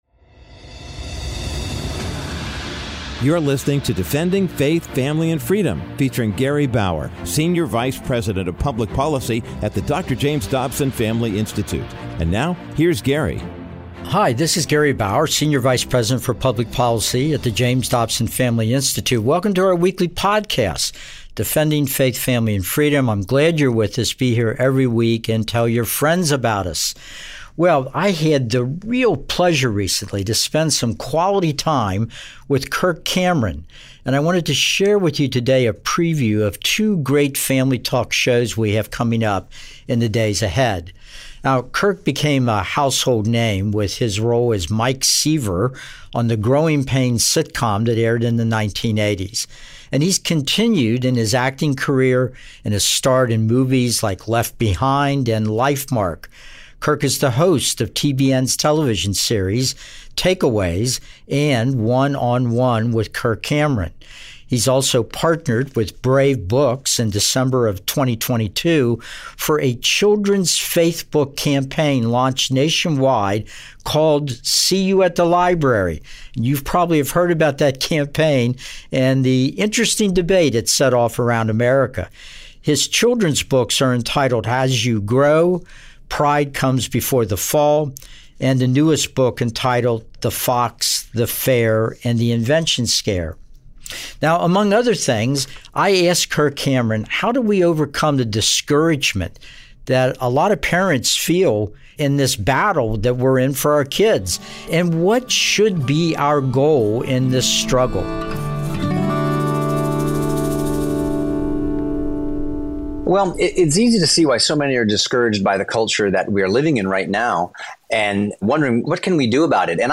In this week's episode of Defending Faith, Family, and Freedom, Gary Bauer chats with Kirk Cameron, American actor, evangelist, and television host. Kirk shares what challenged him to launch his child reading program in public libraries.